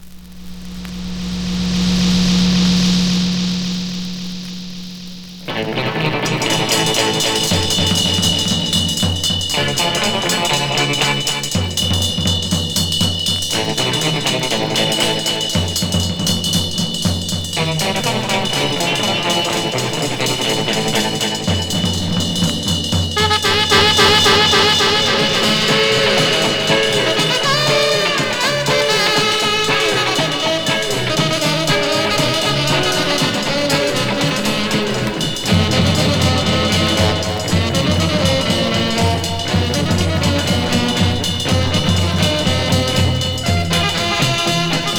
Rock & Roll, Surf　USA　12inchレコード　33rpm　Mono